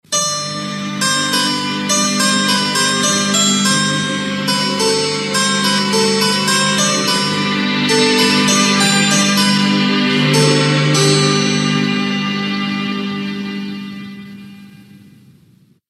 Sintonia de l'emissora
Versió interpretada amb clavicèmbal.